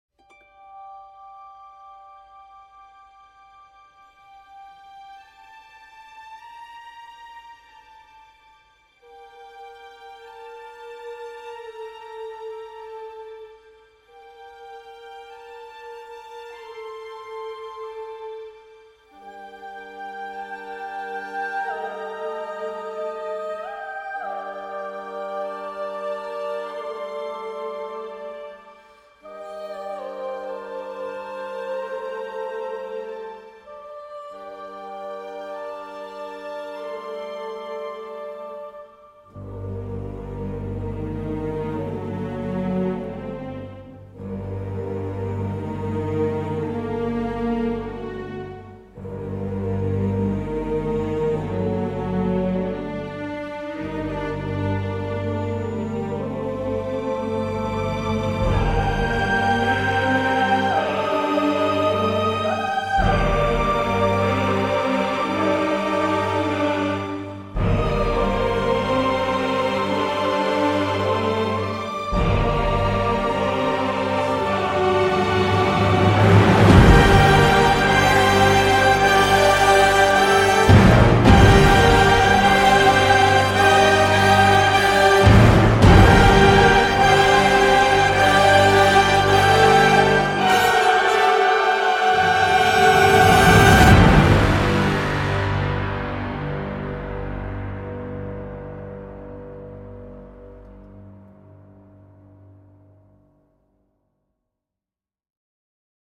Solide musique d’épouvante classique, orchestrale et chorale